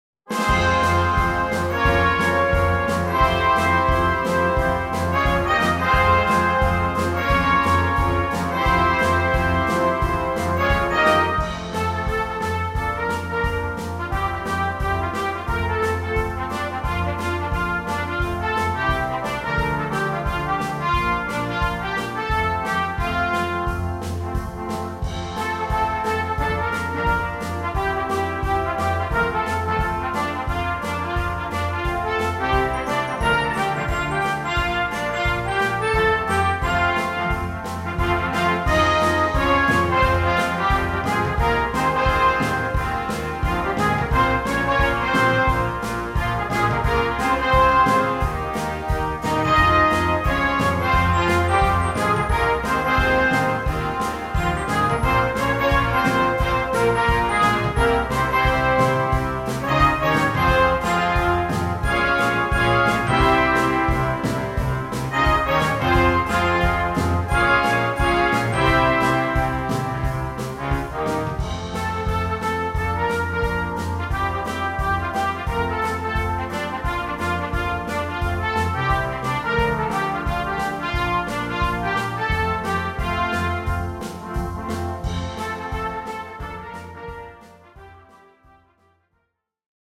Gattung: Moderner Einzeltitel für Blasorchester
Besetzung: Blasorchester